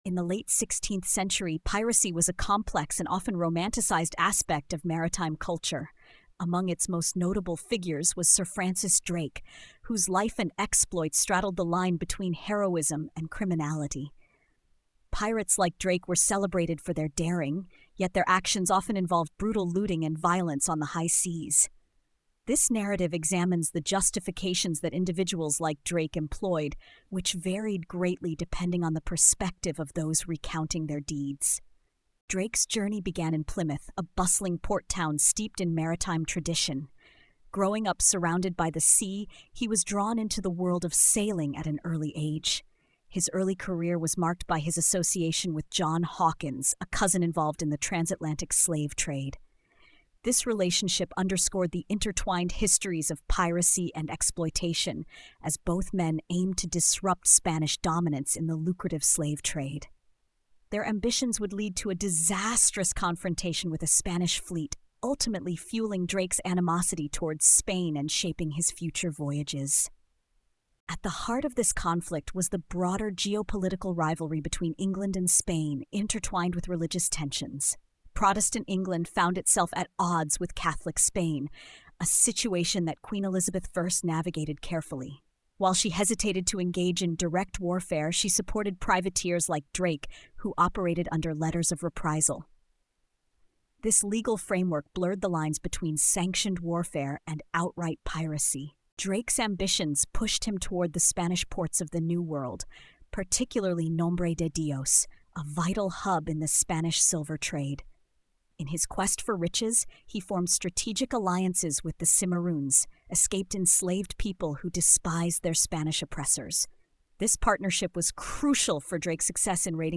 This episode takes viewers deep into the turbulent world of Sir Francis Drake, a man celebrated in England as a national hero yet remembered elsewhere as a pirate, slave trader, and destroyer. Through cinematic reenactments, expert commentary, and historical analysis, the documentary traces Drake’s rise from humble beginnings to becoming one of the most feared and fascinating figures of the 16th century.